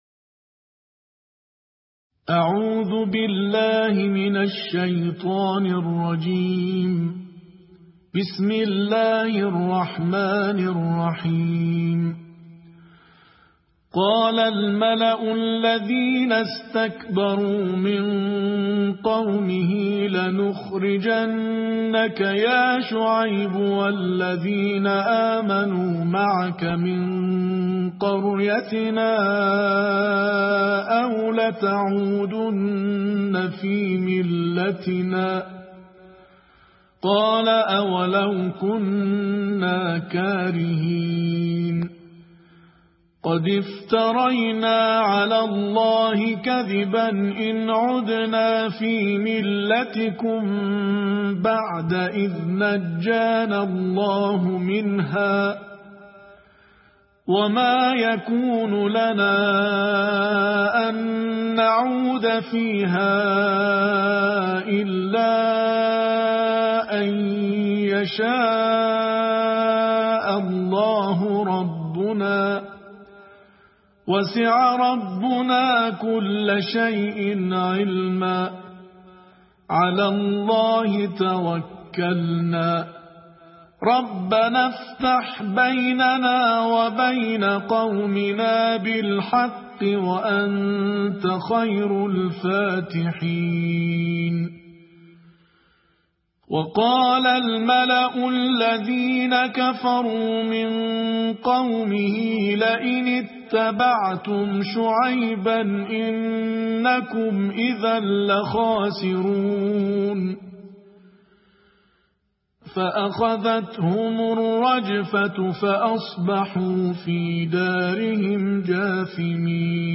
نړیوال قارِي ،د قرآن کریم د نهمې(9) سپارې یا جزوې د ترتیل قرائت